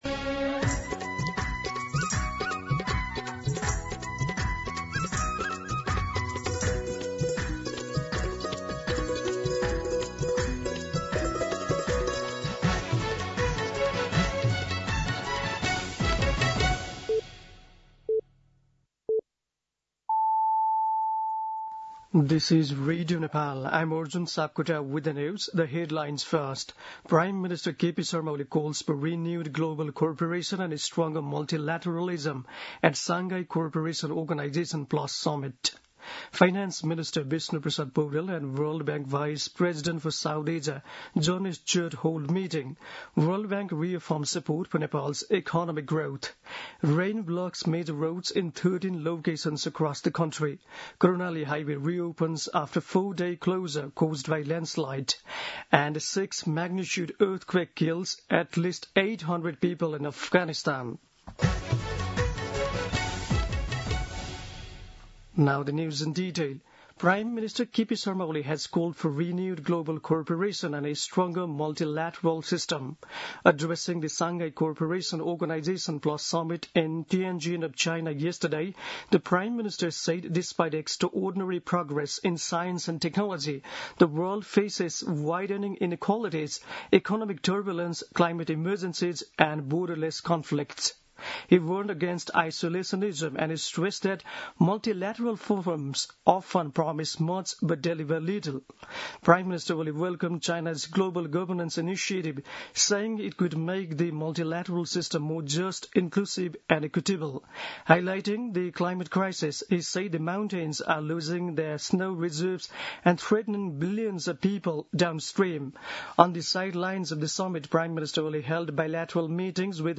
दिउँसो २ बजेको अङ्ग्रेजी समाचार : १७ भदौ , २०८२
2pm-English-News-1.mp3